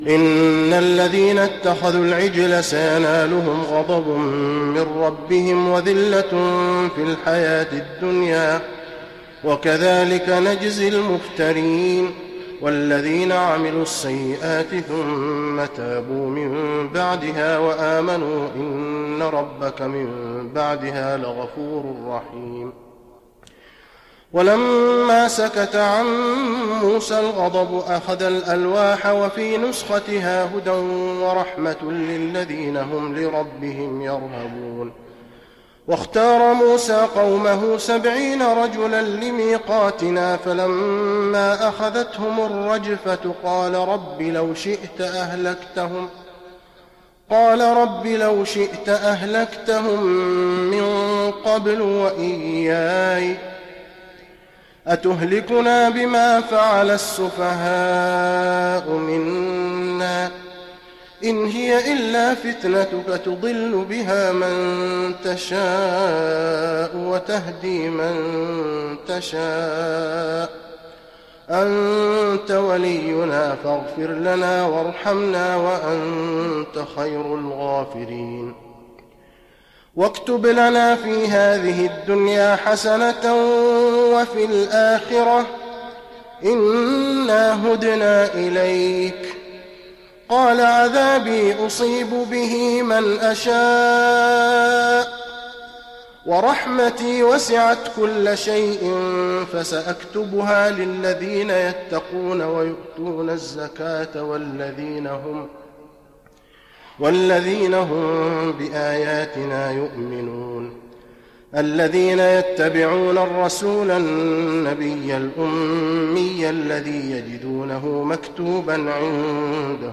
تراويح رمضان 1415هـ من سورتي الأعراف (152-206) و الأنفال (1-19) Taraweeh Ramadan 1415H from Surah Al-A’raf and Al-Anfaal > تراويح الحرم النبوي عام 1415 🕌 > التراويح - تلاوات الحرمين